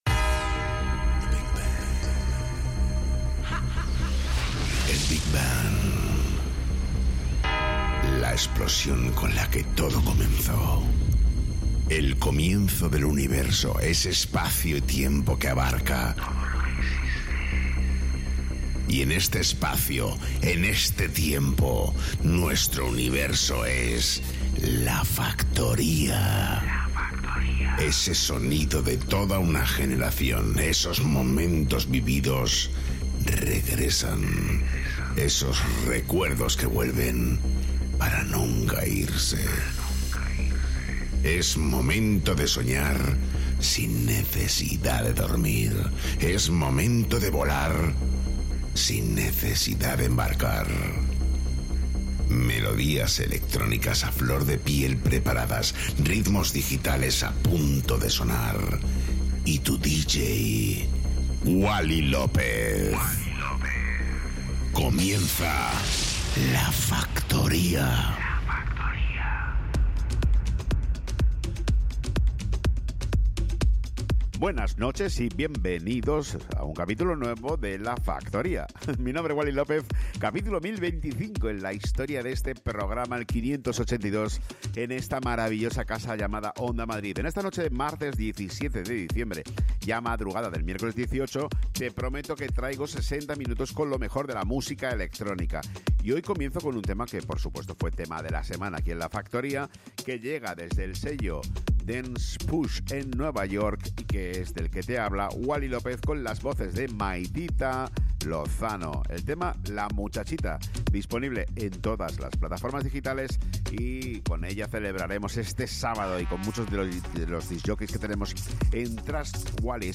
Wally López, el DJ más internacional de Madrid retoma La Factoría para todos los madrileños a través de Onda Madrid.